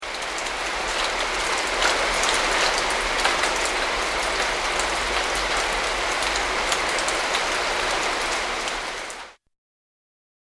Nature
Rain(164K) -Jungle(164K)